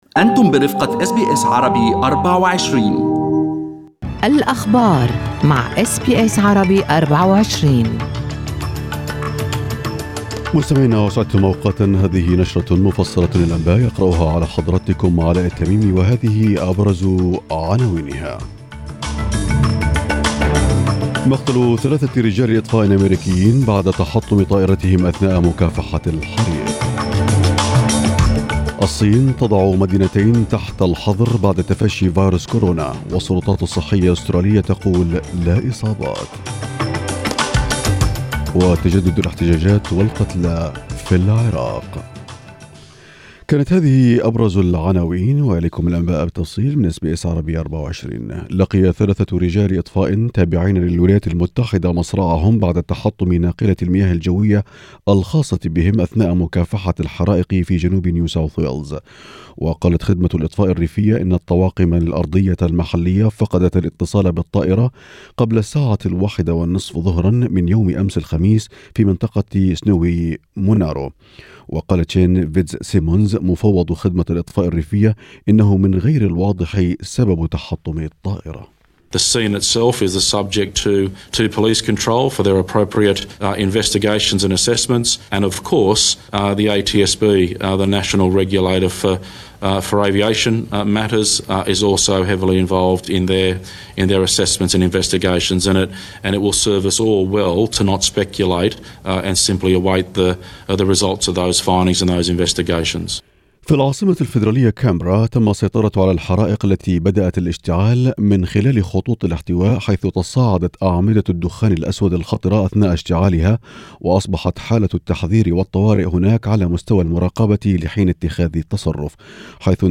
نشرة أخبار الصباح 24/01/2020
Arabic News Bulletin Source: SBS Arabic24